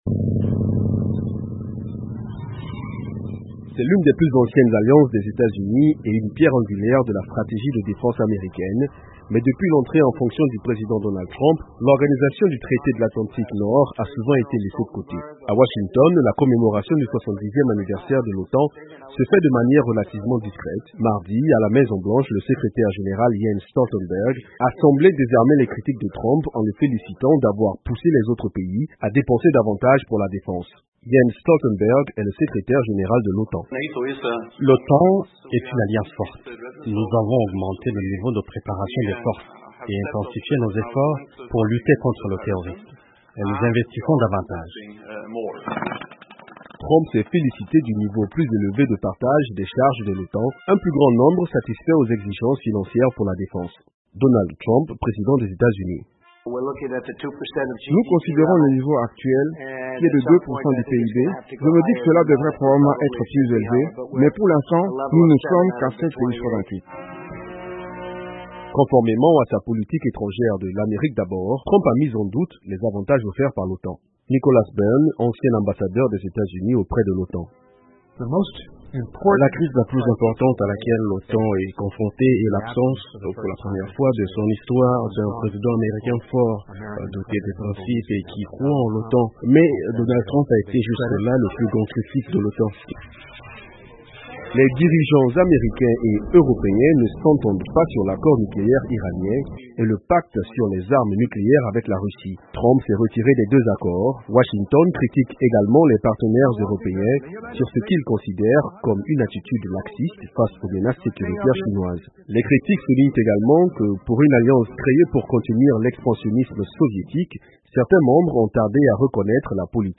De la Maison Blanche, reportage de